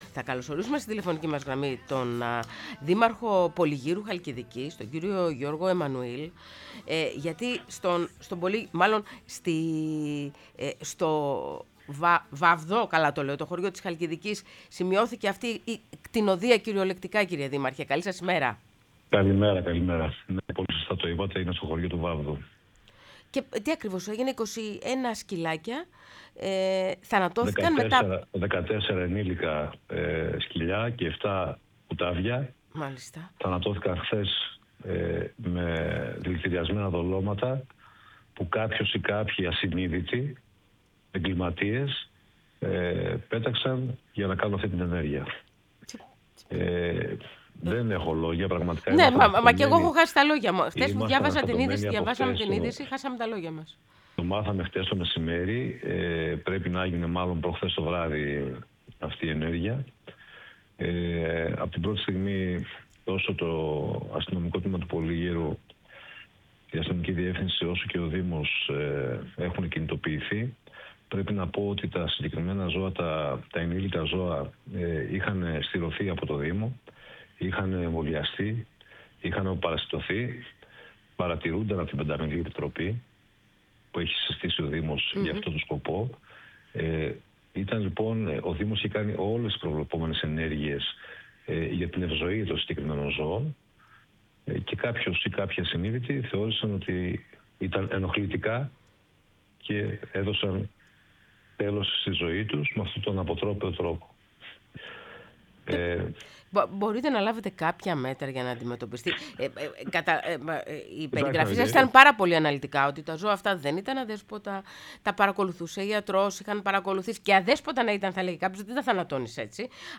Ο Γιώργος Εμμανουήλ, Δήμαρχος Πολυγύρου Χαλκιδικής, μίλησε στην εκπομπή «Πρωινές Διαδρομές»